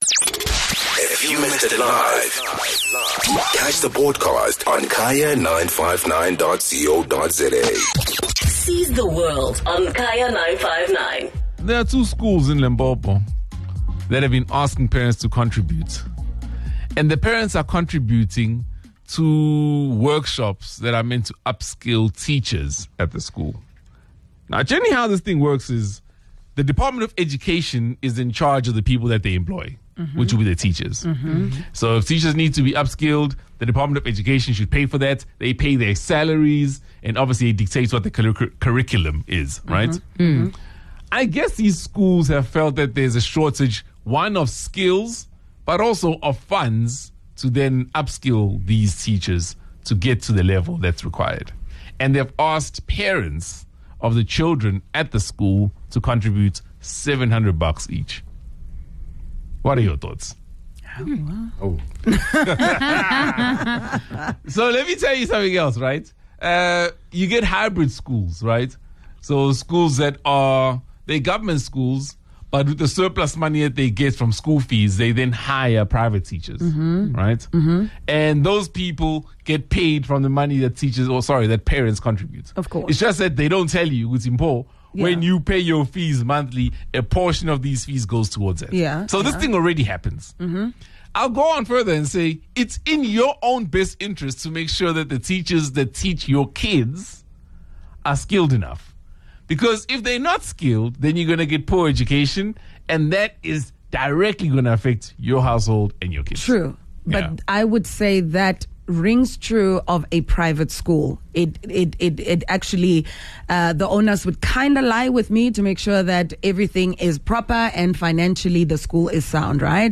Schools were asked to pay an amount of R700 by Tuesday to cover the cost of breakfast and lunch for each member for three days. The team debated whether this is an appropriate request by the district or not.